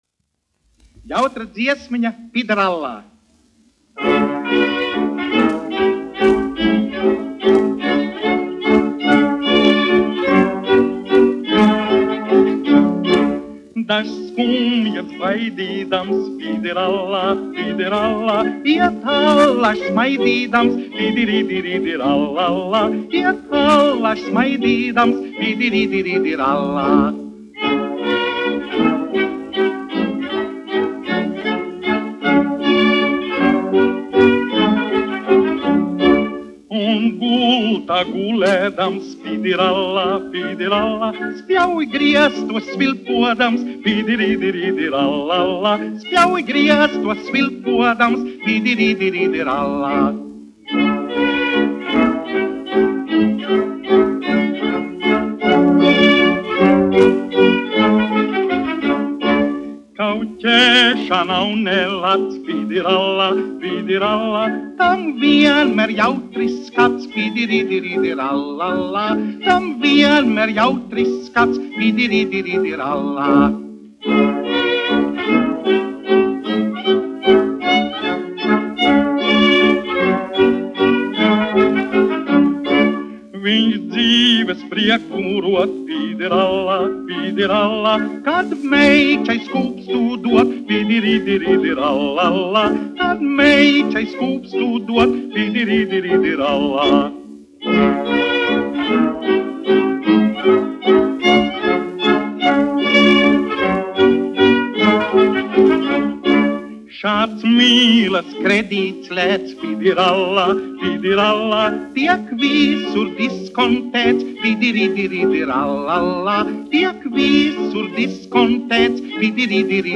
1 skpl. : analogs, 78 apgr/min, mono ; 25 cm
Humoristiskās dziesmas
Populārā mūzika
Skaņuplate
Latvijas vēsturiskie šellaka skaņuplašu ieraksti (Kolekcija)